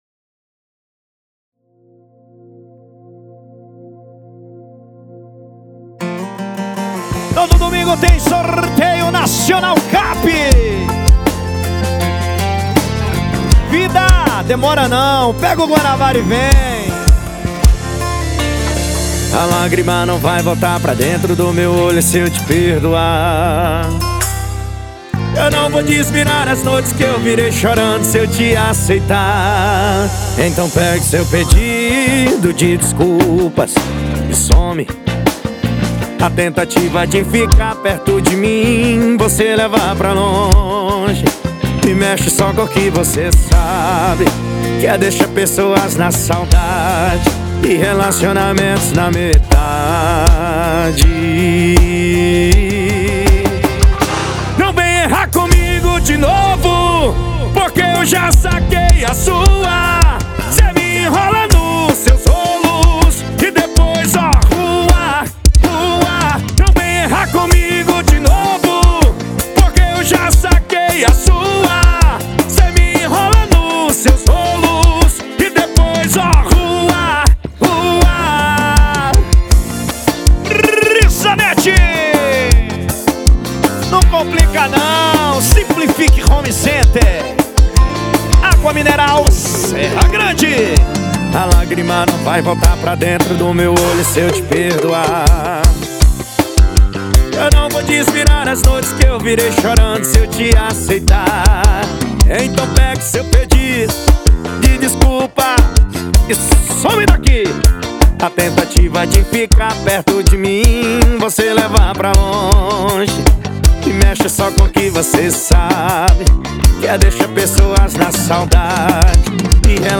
2024-02-14 22:40:16 Gênero: Forró Views